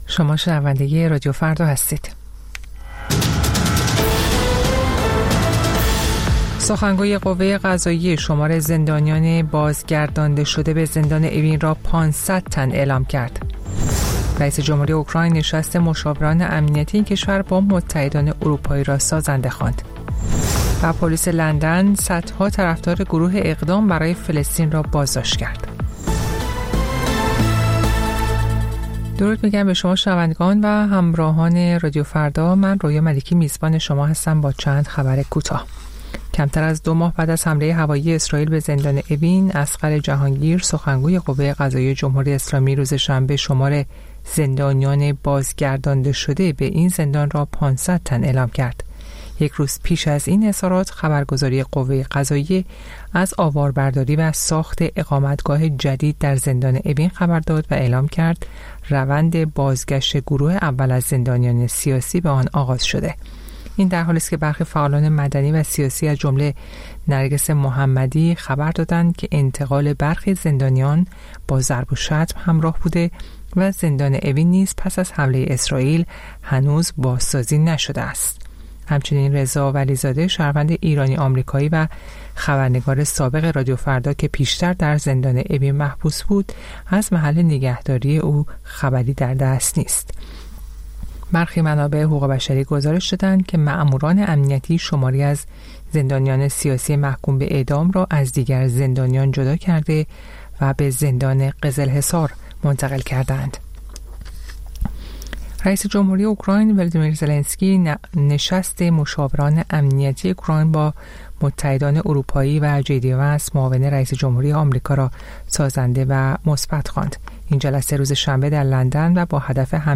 سرخط خبرها ۶:۰۰
پخش زنده - پخش رادیویی